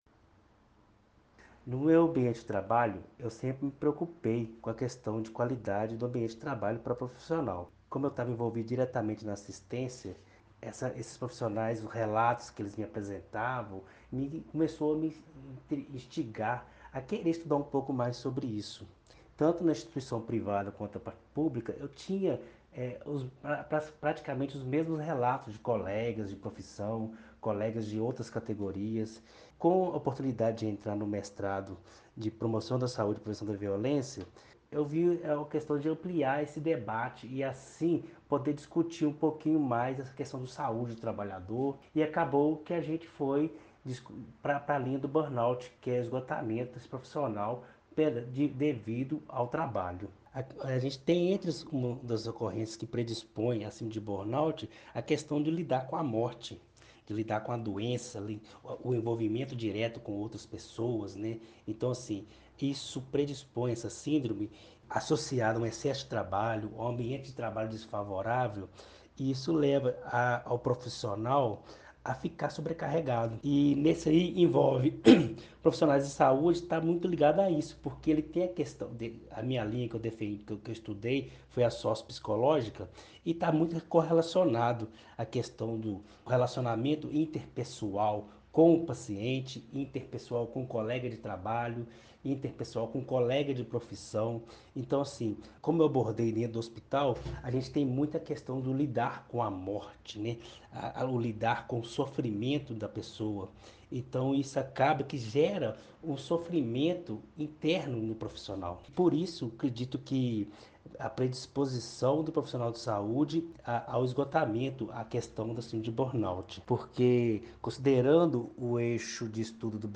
Depoimento